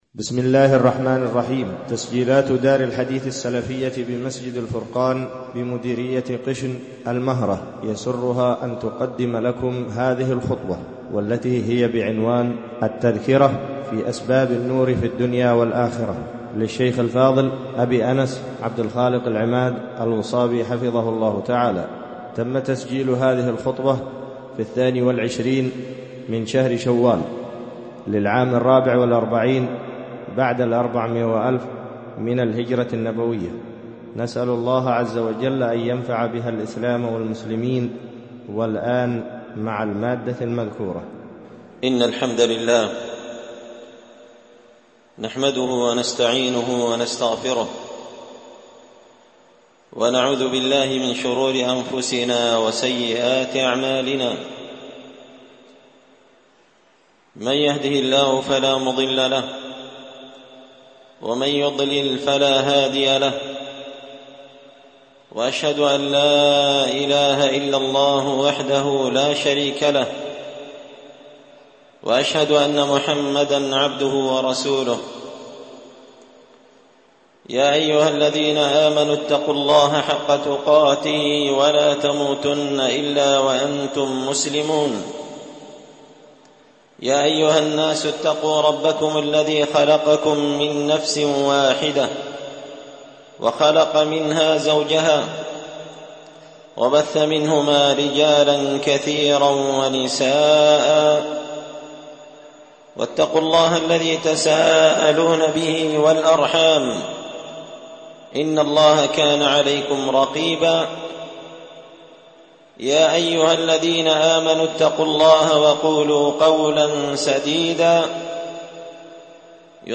خطبة جمعة بعنوان التذكرة في أسباب النور في الدنيا والآخرة
ألقيت هذه الخطبة في مسجد الفرقان قشن-المهرة-اليمن